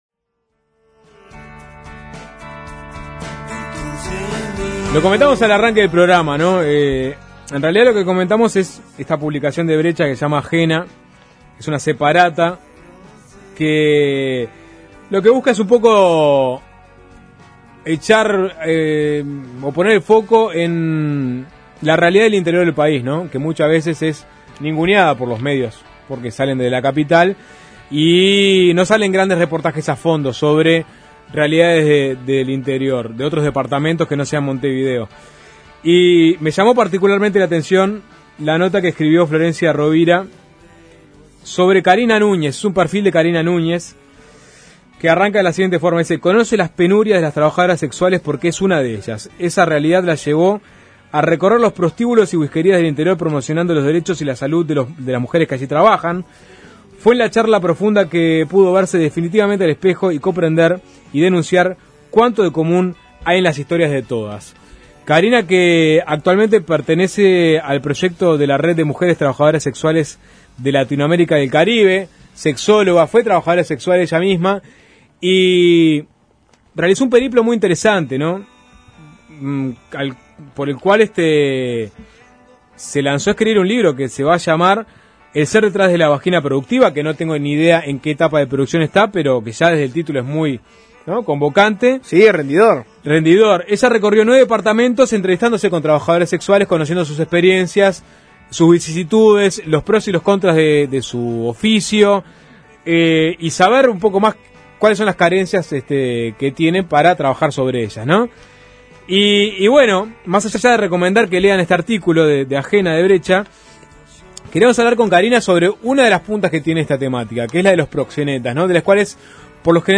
Conversamos con la sexóloga y trabajadora sexual